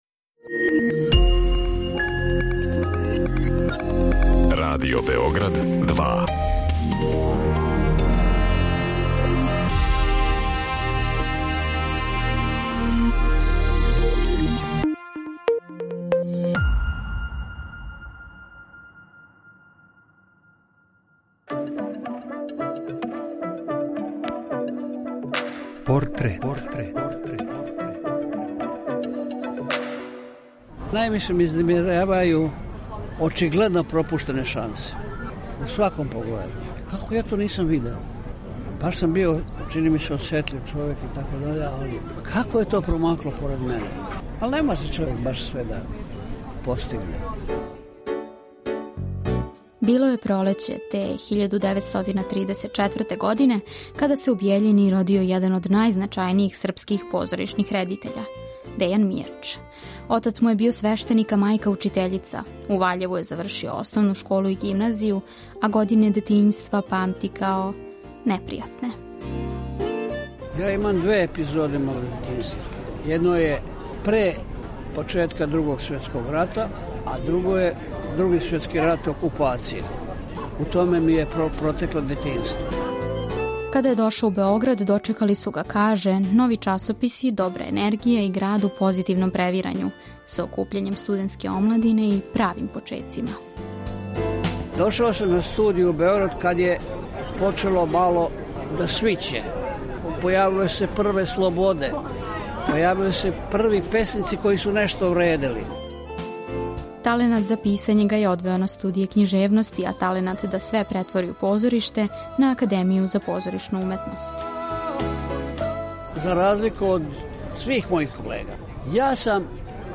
Приче о ствараоцима, њиховим животима и делима испричане у новом креативном концепту, суптилним радиофонским ткањем сачињеним од: интервјуа, изјава, анкета и документраног материјала.
Осим Мијача, чућете његове пријатеље и сараднике - глумце Воју Брајовића и Ирфана Менсура, као и академика, драмског писца, песника и есејисту Љубомира Симовића.